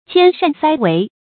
迁善塞违 qiān shàn sāi wéi
迁善塞违发音